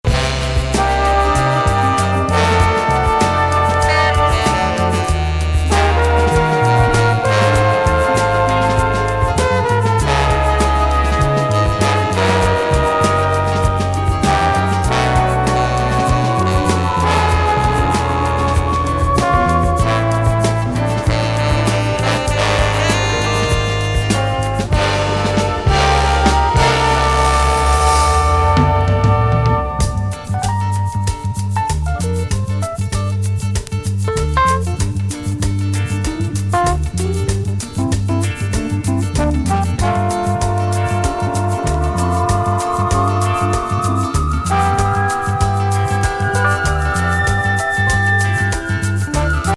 ビッグバンド編成。